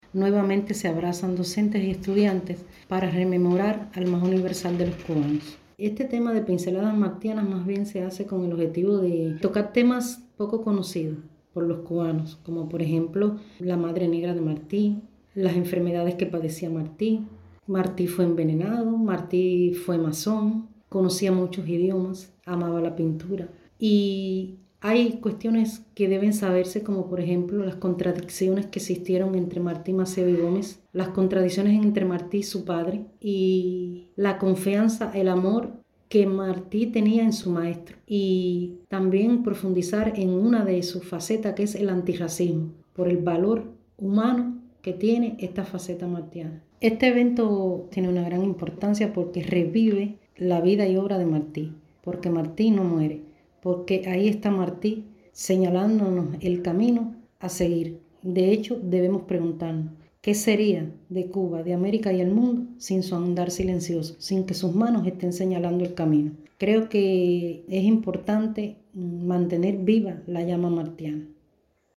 Sobre esta experiencia comentó a nuestra emisora: